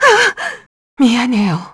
Juno-Vox_Dead_kr.wav